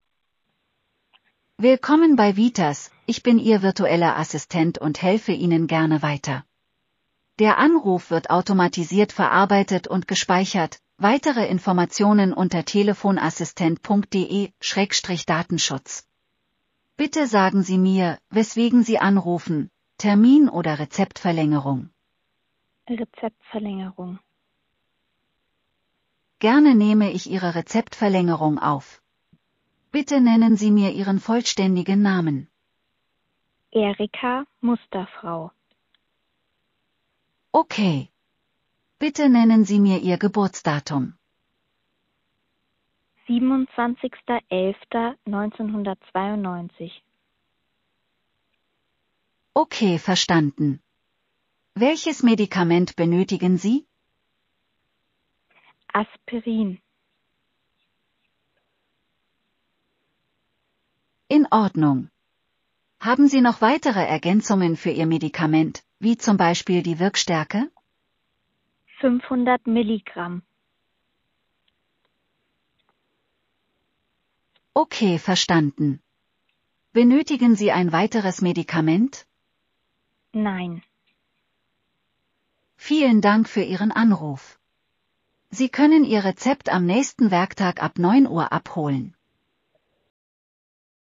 Beispielszenario_medizinischer-Anwendungsfall_Audio.mp3